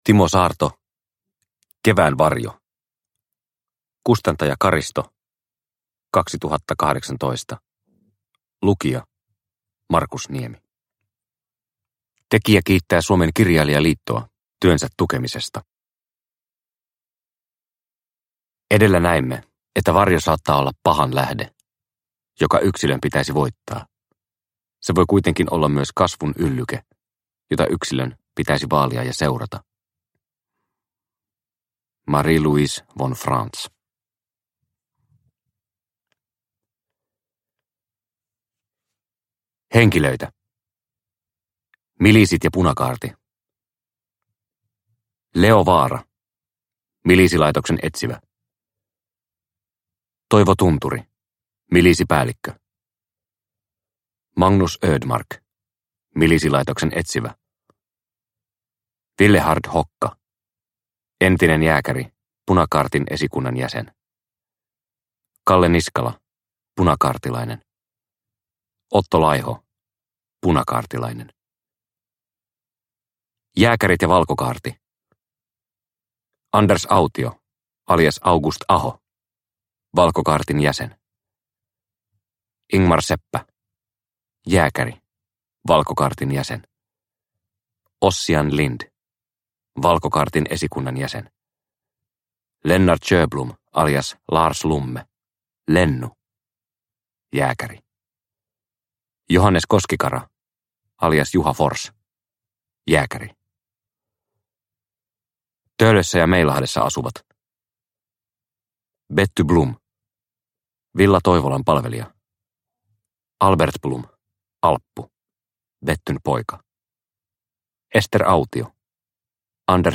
Kevään varjo – Ljudbok – Laddas ner